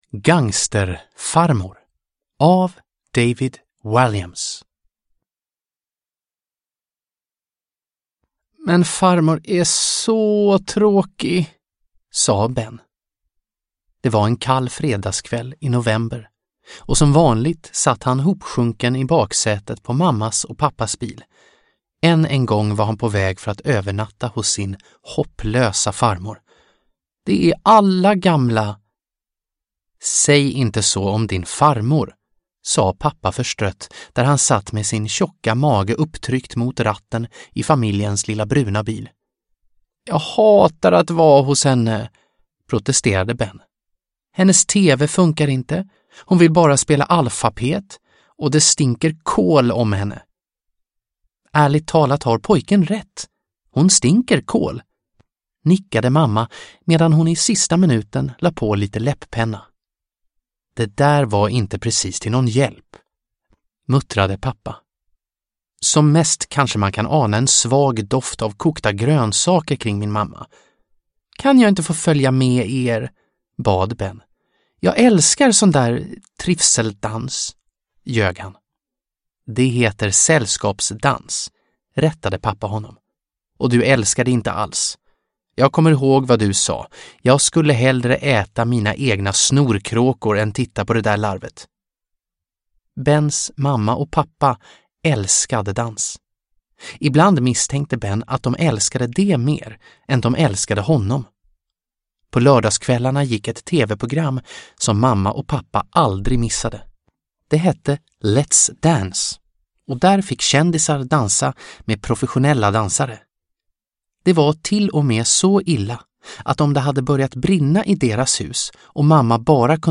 Gangsterfarmor – Ljudbok – Laddas ner